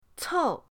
cou4.mp3